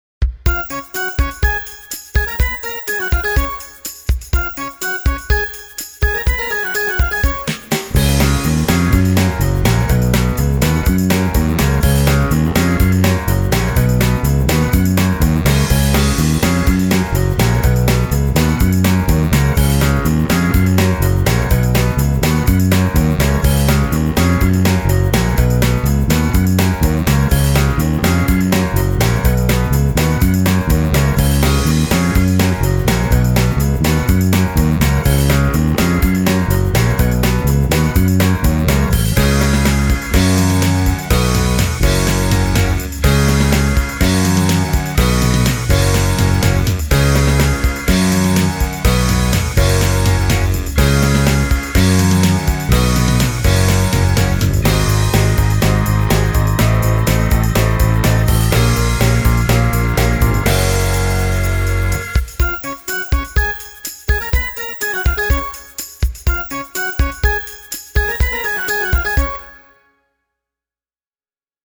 I used my own 1976 Fender as a benchmark, and the Green P-Bass growls just like it should. The low register is punchy, and the top end is open, but never sharp or brittle.
I have chosen to record a little Motown-tribute as a demo song. The Green P-Bass went through a SansAmp Bass Driver and then into a Focusrite interface: